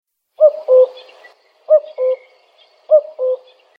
Coucou gris
Cuculus canorus